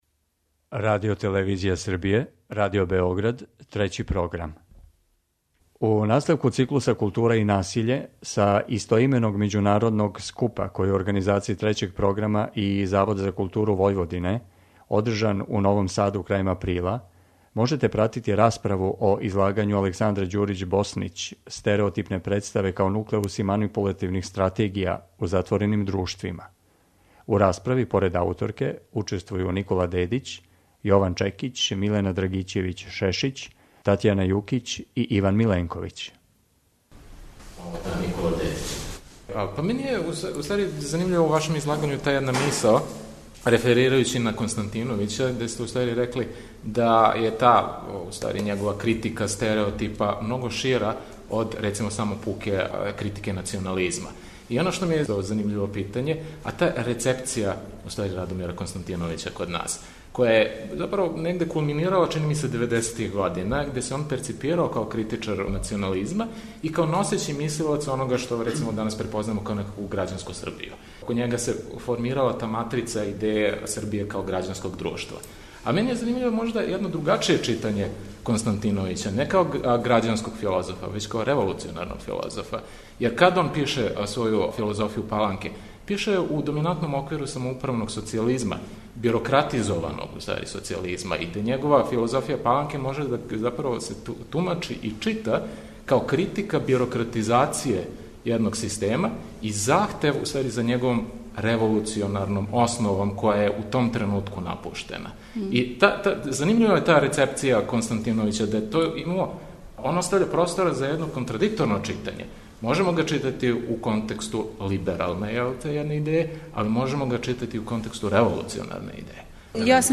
Циклус КУЛТУРА И НАСИЉЕ одржан је у Новом Саду крајем априла, у организацији Трећег програма и Завода за културу Војводине. Са овог скупа емитујемо предавања и разговоре о предавањима.